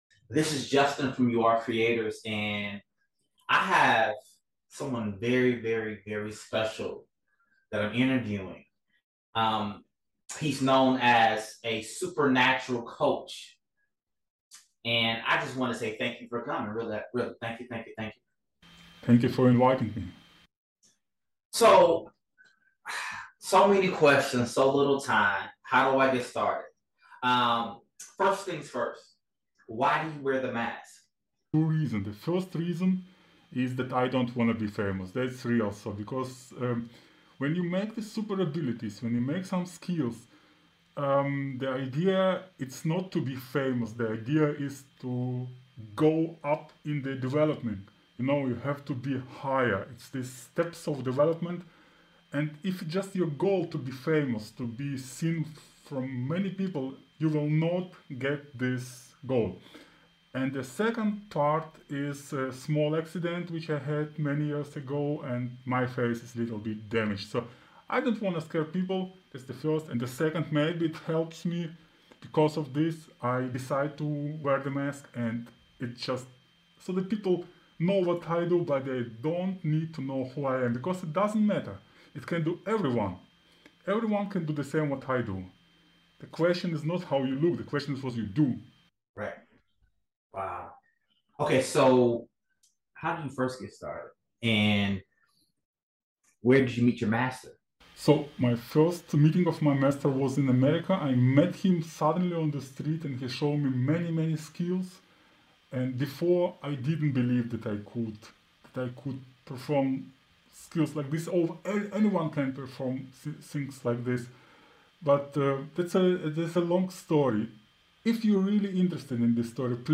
Interview - Supernatural Teacher: Shows You How To Levitate, Use Telekinesis And So Much More